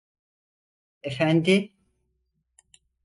Pronunciat com a (IPA) /eˈfændi/